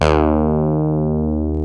ODSSY BS 2.wav